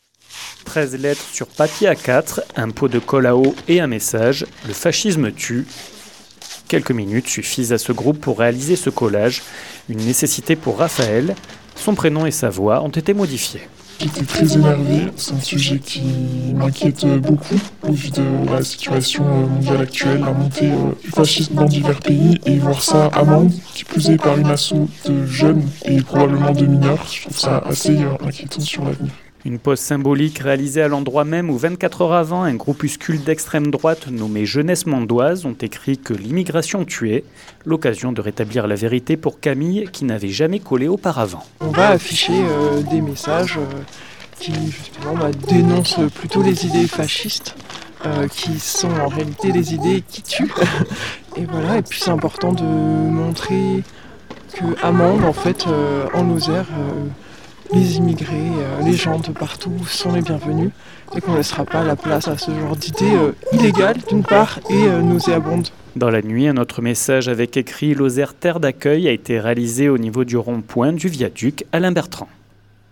Dans la nuit du jeudi 25 au vendredi 26 septembre, un groupe de citoyens et citoyennes engagés a réalisé deux collages en réponse aux messages d’extrême droite apparus hier dans les rues de Mende. 48FM était sur place pour recueillir leurs témoignages.
REPORTAGE